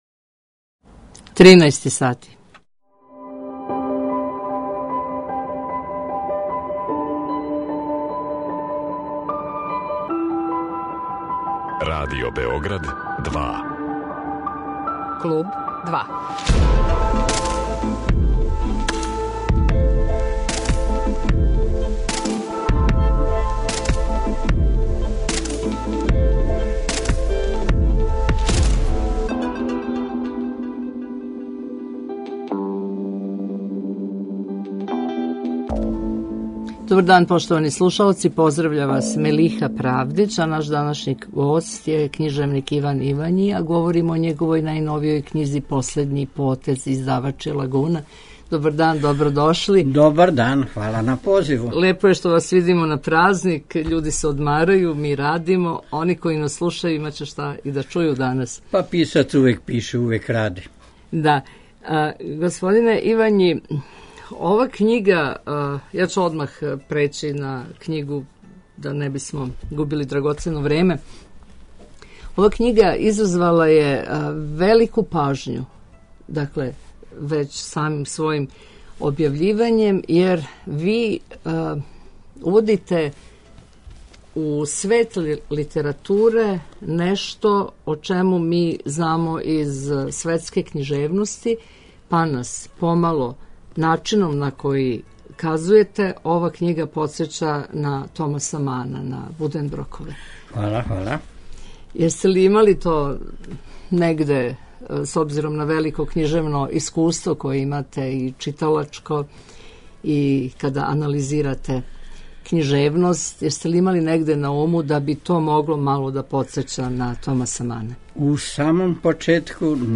Гост 'Клуба 2' биће књижевник Иван Ивањи, а говоримо о његовој најновијој књизи 'Последњи потез' (Лагуна).